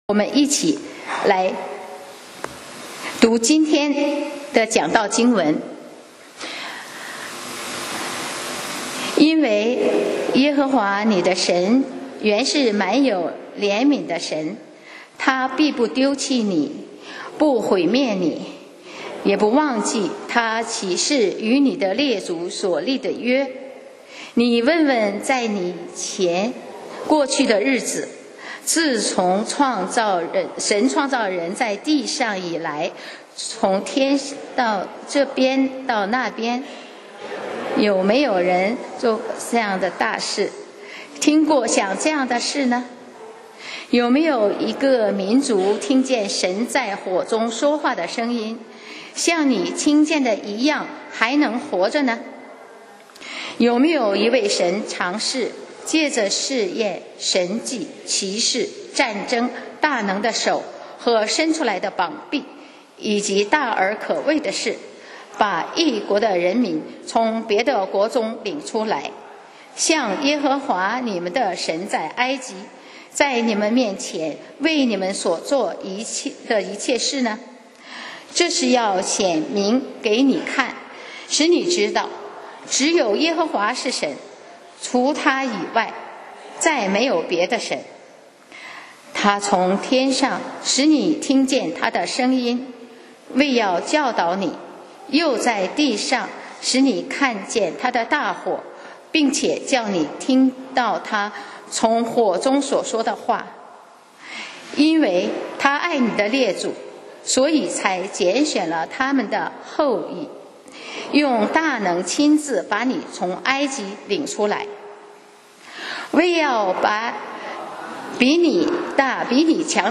講道 Sermon 題目 Topic：爱的代价 經文 Verses：申命记4：31-40. 31．因为耶和华你的 神原是满有怜悯的 神，他必不丢弃你，不毁灭你，也不忘记他起誓与你的列祖所立的约。